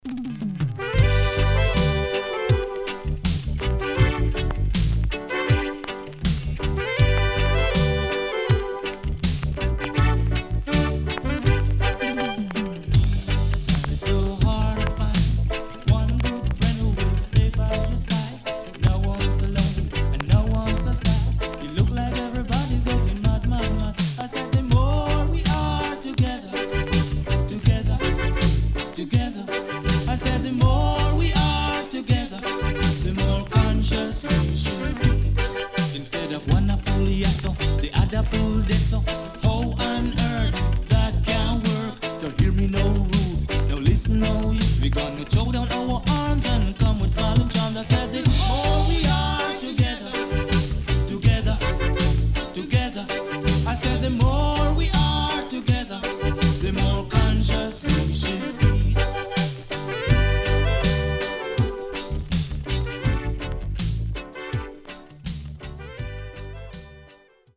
CONDITION SIDE A:VG(OK)〜VG+
SIDE A:所々チリノイズがあり、少しプチノイズ入ります。